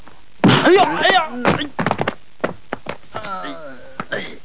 SFX被人打倒的连续哎呦声音效下载
SFX音效